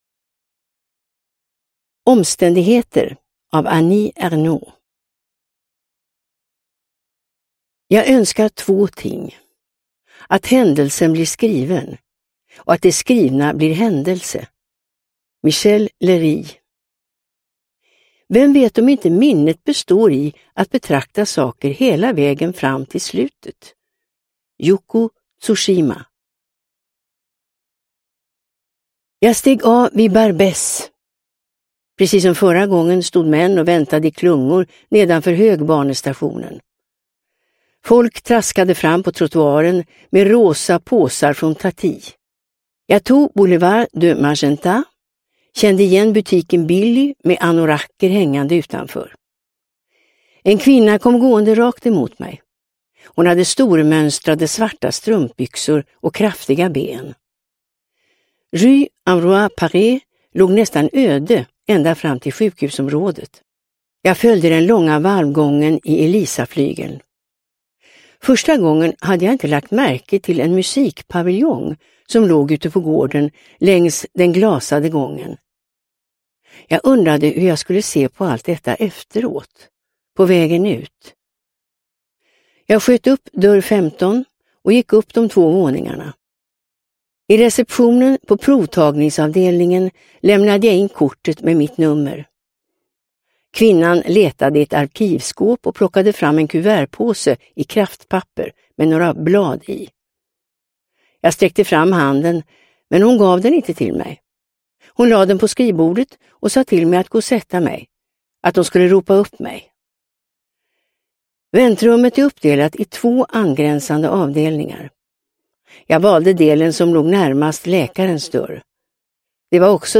Omständigheter – Ljudbok – Laddas ner